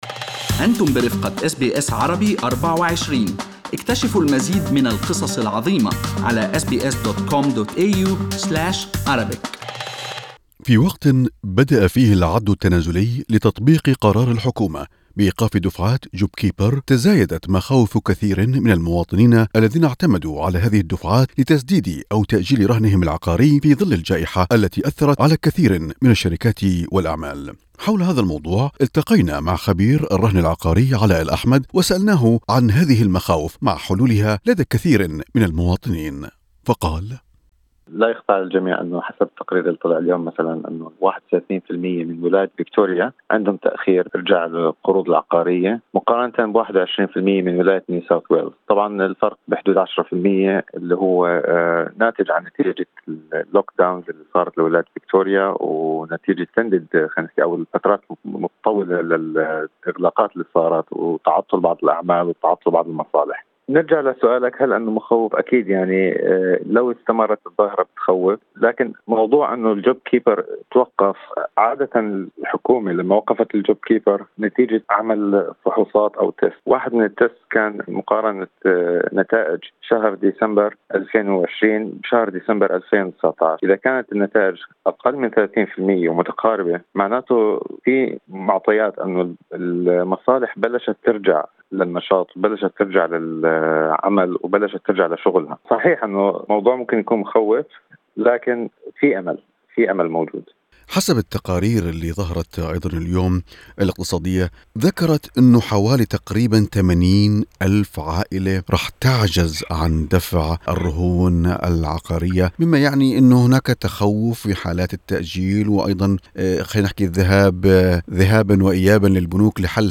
للقاء مع خبير الرهن العقاري